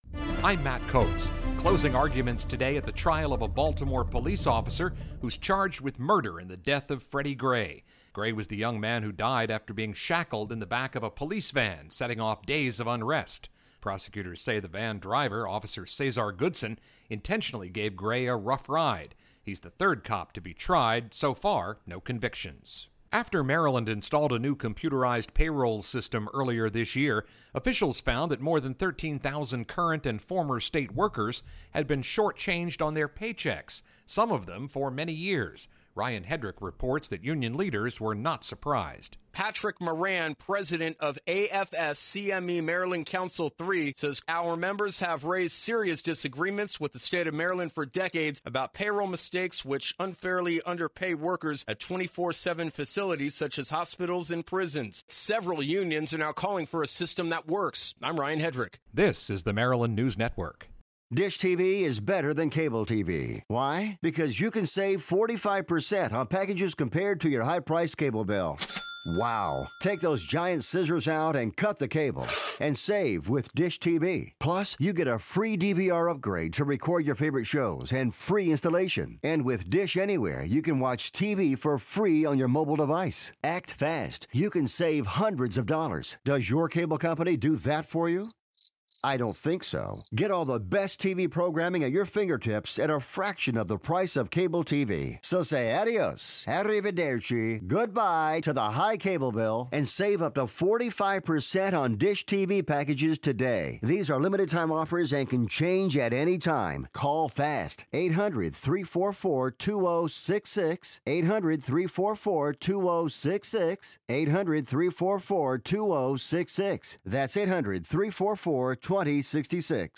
Afternoon headlines for Monday, June 20, 2016 from the Maryland News Network.